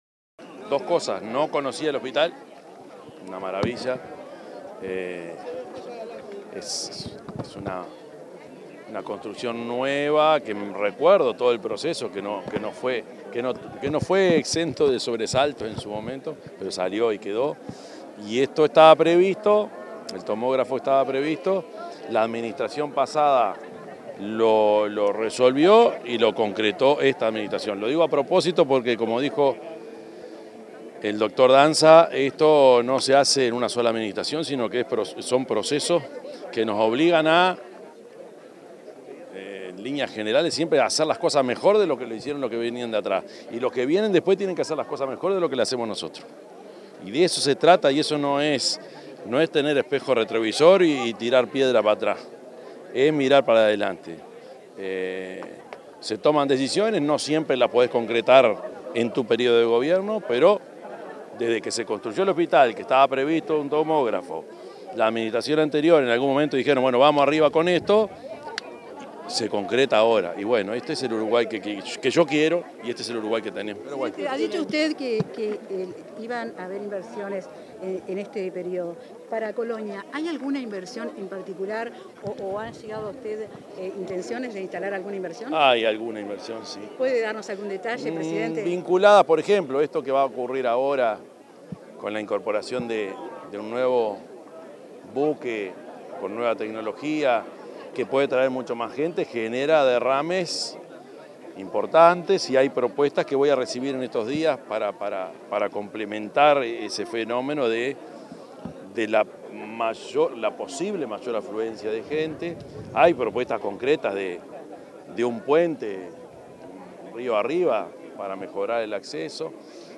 Tras la inauguración de un tomógrafo en el hospital de Colonia, el presidente de la República, Yamandú Orsi, realizó declaraciones a los medios